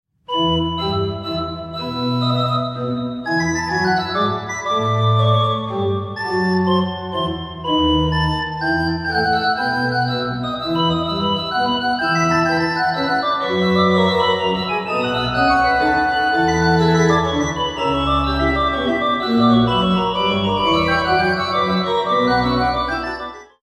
Garnisons Kirke, Copenhagen